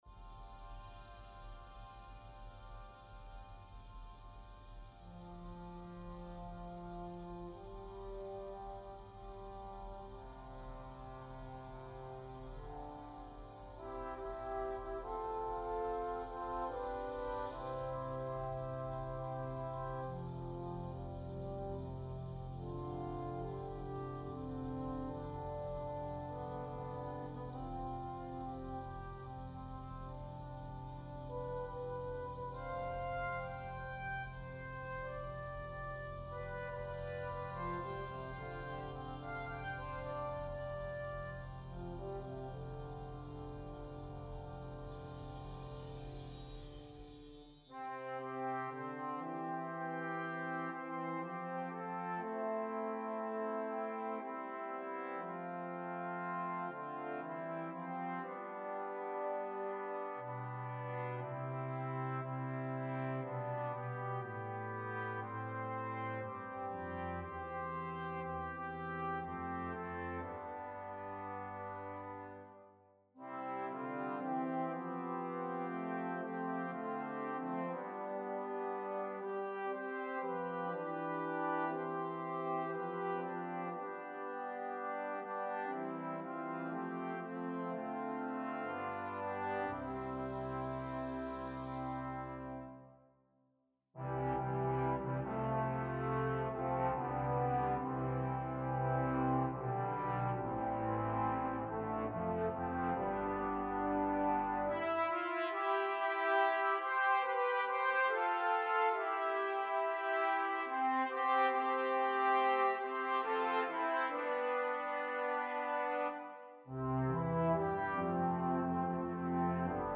Besetzung: Brass Band